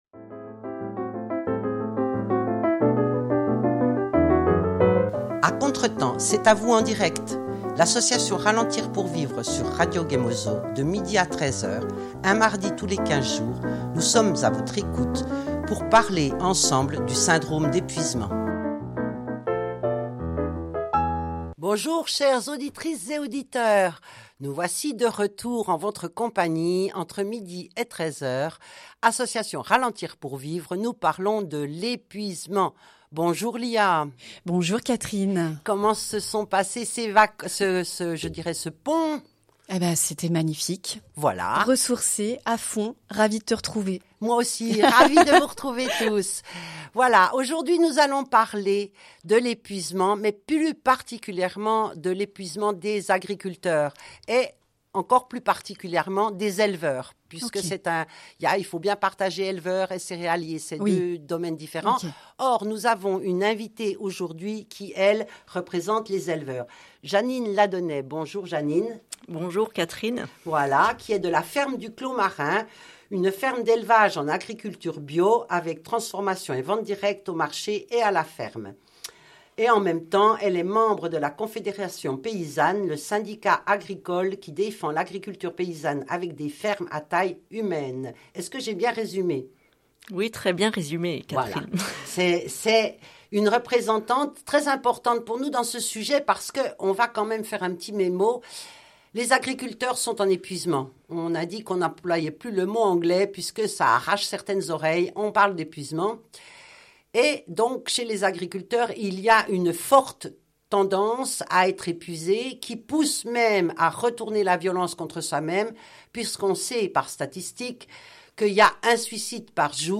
Au programme : un débat poignant sur l’épuisement des agriculteurs et les défis du monde agricole aujourd’hui.